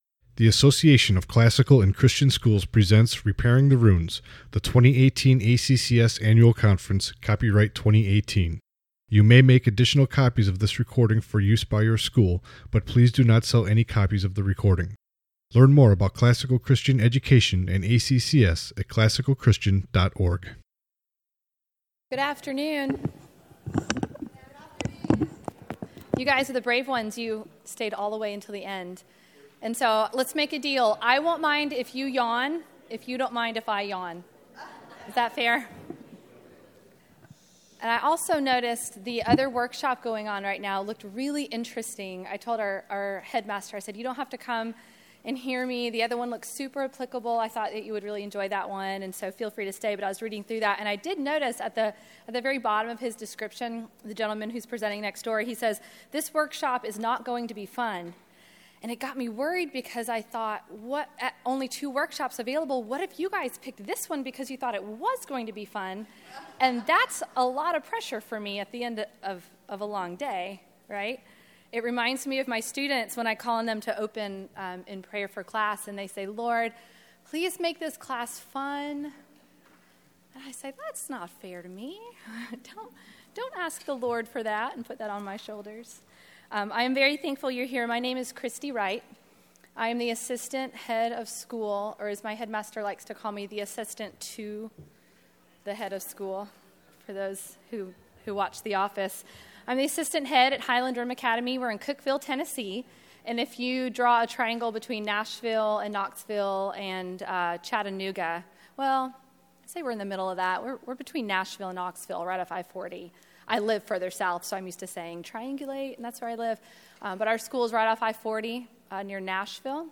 2018 Leaders Day Talk | 51:45 | Leadership & Strategic